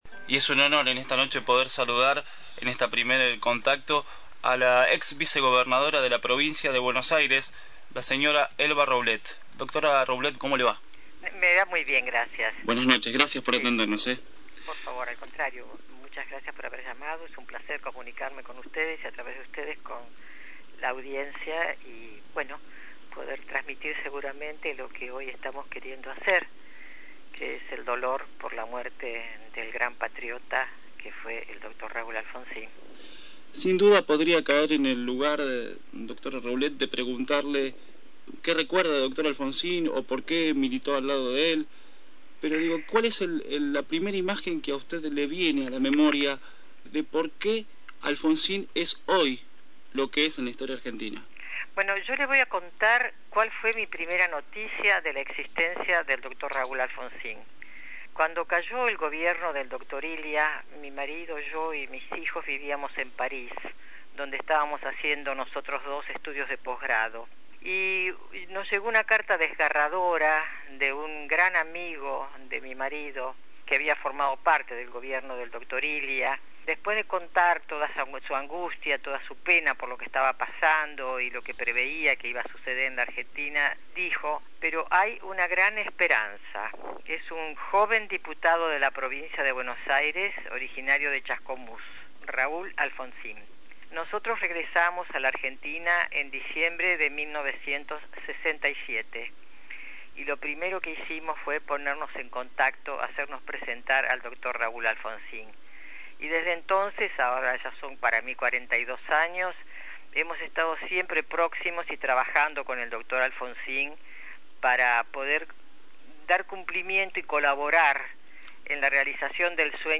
Tipo de documento: Testimonio